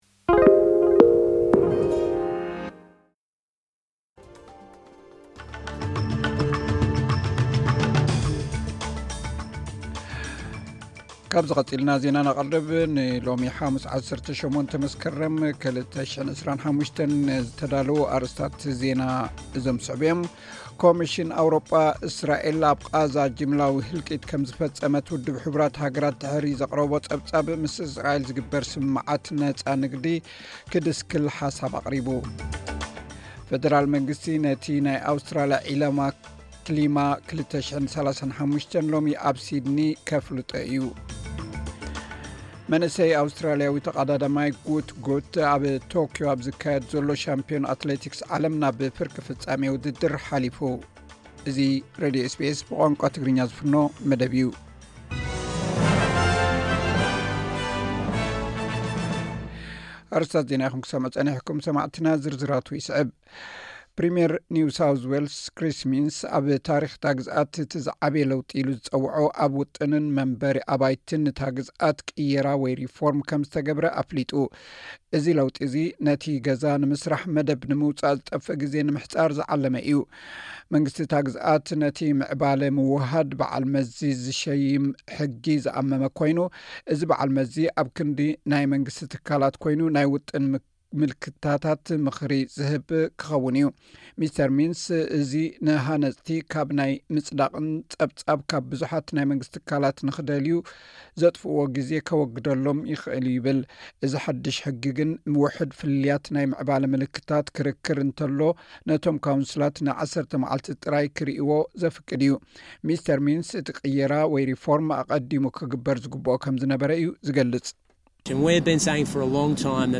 ዕለታዊ ዜና ኤስ ቢ ኤስ ትግርኛ (18 መስከረም 2025)